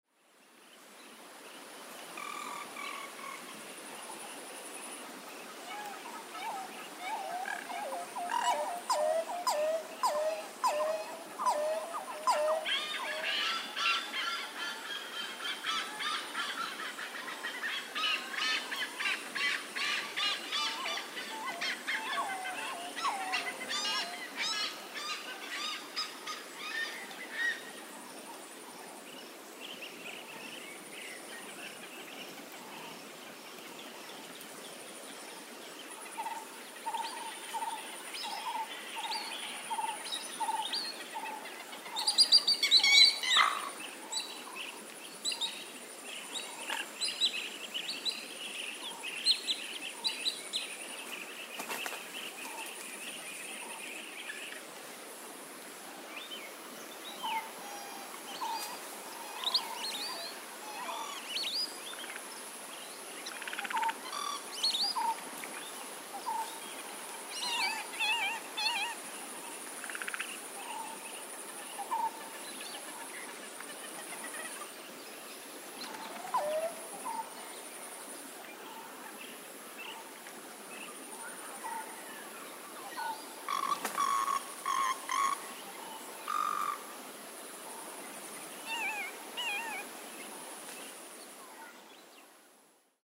Sounds of birds of many species and other animals including insects dominate in the daytime soundscapes, and in the nighttime frogs and numerous insects with at times an owl or nightjar.
Soundscape at the wetland
ZOOM001_Bird-sounds-at-Villu_MP3.mp3